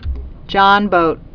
(jŏnbōt)